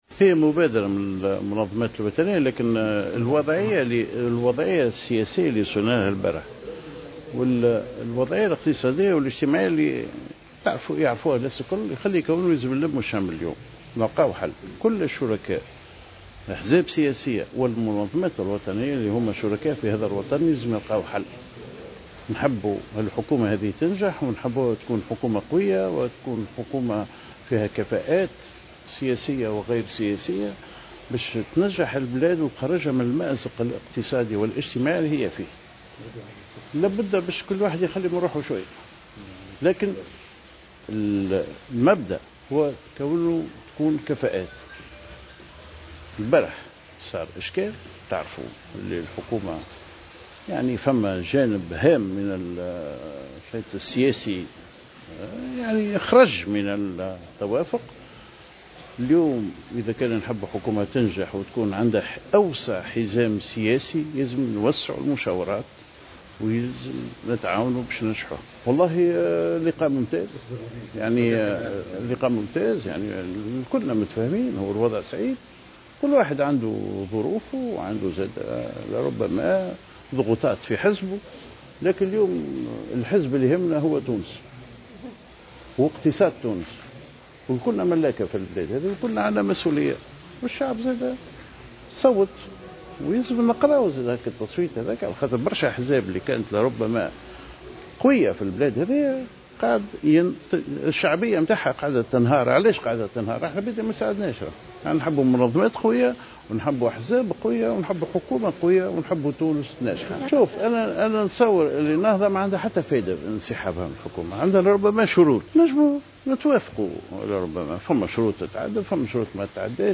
وقال ماجول، في تصريح لمراسلة الجوهرة أف ام، عقب لقائه اليوم الأحد، براشد الخريجي الغنوشي رئيس مجلس نواب الشعب، ونور الدين الطبوبي الأمين العام للاتحاد العام التونسي للشغل، في مقر البرلمان، قال إنه بالإمكان الوصول إلى توافق بين الأحزاب، عبر رجوعها إلى المشاورات لتشكيل حكومة تتمتع بحزام سياسي واسع.